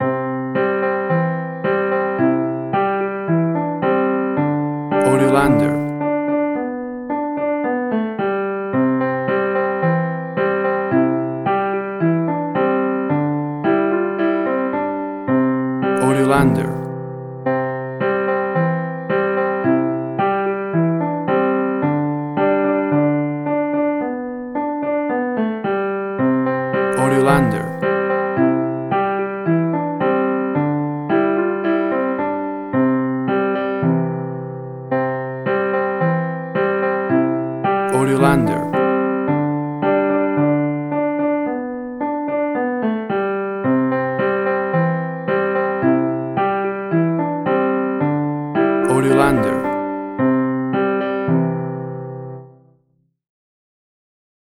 WAV Sample Rate: 16-Bit stereo, 44.1 kHz
Tempo (BPM): 110